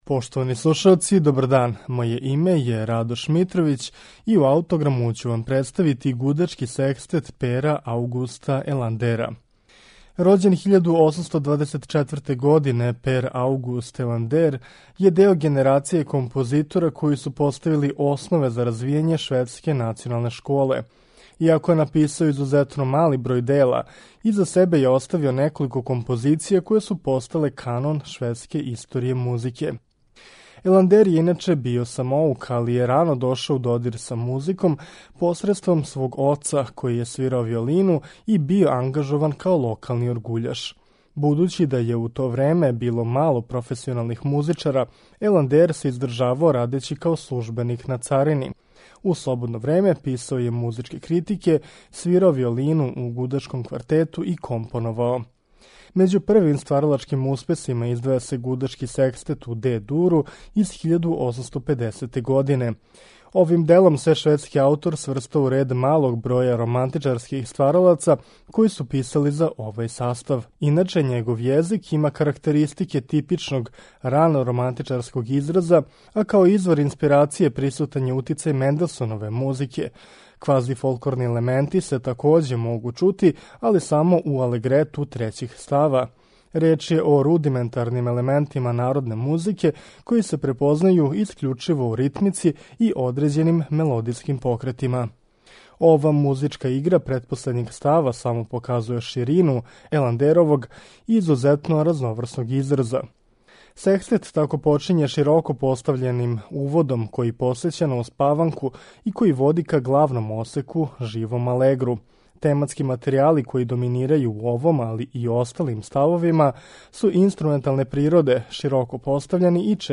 Гудачки секстет Пера Аугуста Еландера
У емисији Аутограм, Гудачки секстет Пера Аугуста Еландера слушаћете у извођењу ансамбла Камерних солиста из Упсале.